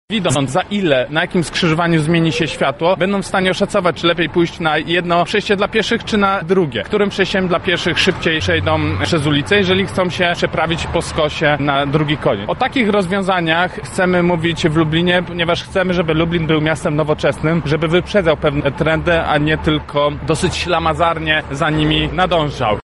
To rozwiązanie pomoże także pieszym – mówi poseł Jakub Kulesza, kandydat na prezydenta Lublina